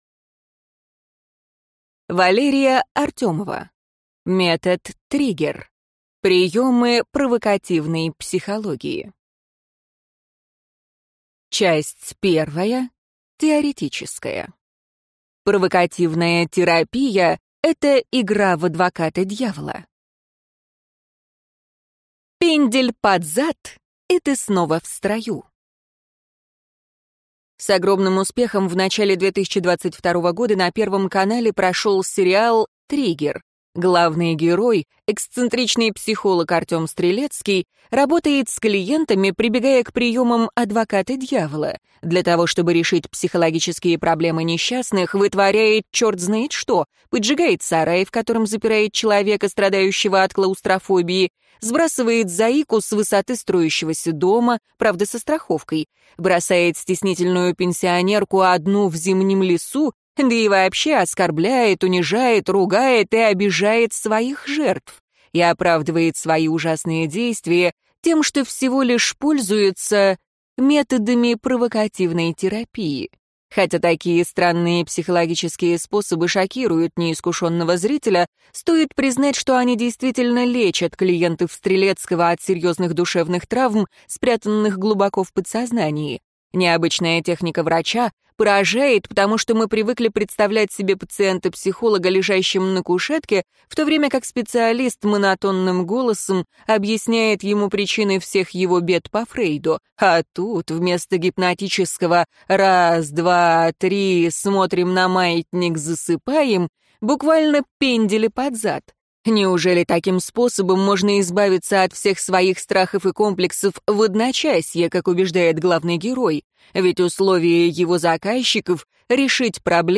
Аудиокнига Метод «Триггер». Приемы провокативной психологии | Библиотека аудиокниг